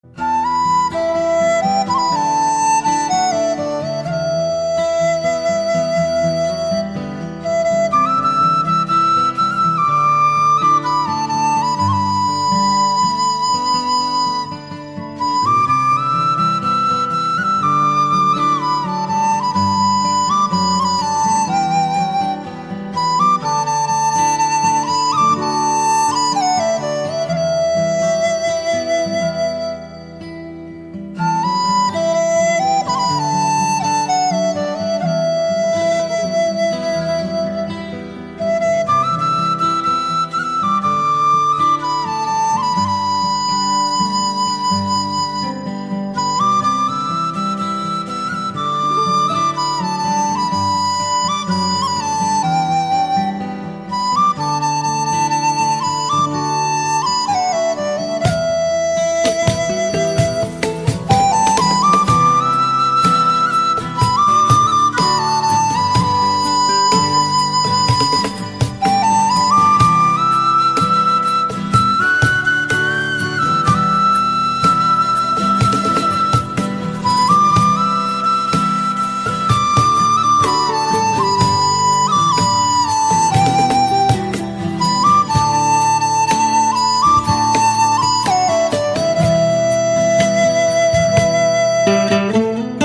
cực phẩm cổ phong, giai điệu buồn da diết
bản nhạc không lời